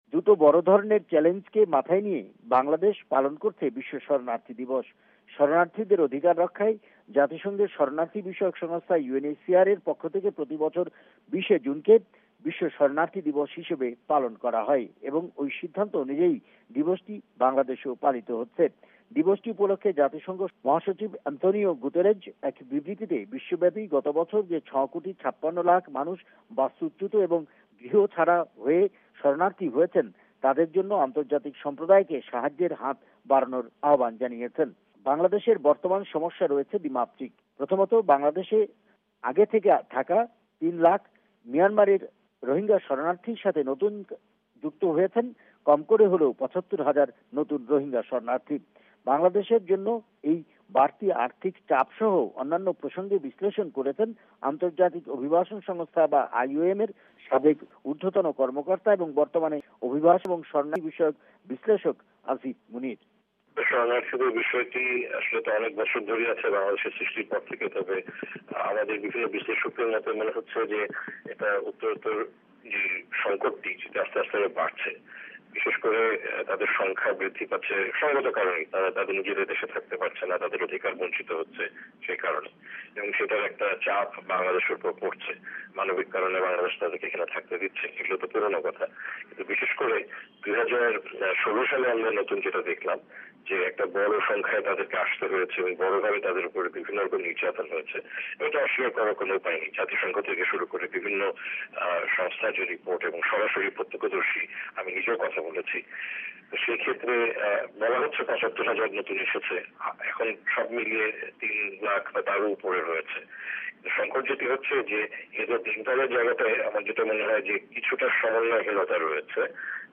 (সাক্ষাৎকার)